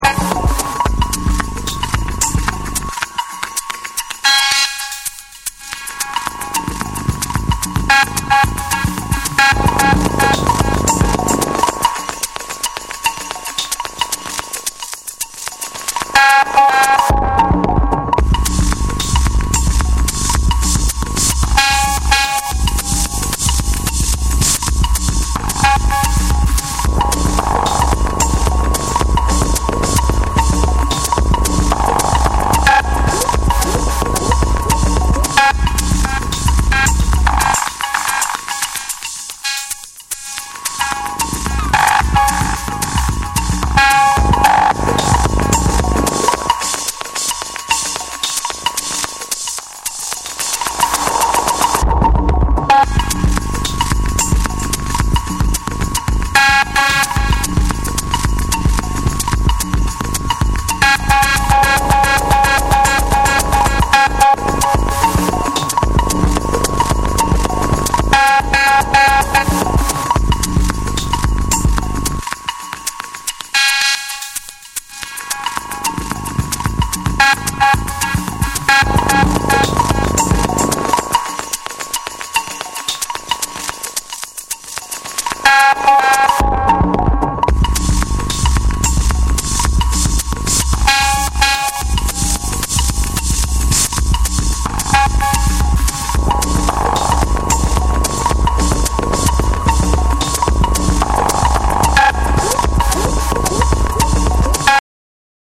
緻密で繊細な音の粒とジワジワと底の方か震わせるベースラインが交わり、実験的な要素も取り入れたディープ・グルーヴを披露する
BREAKBEATS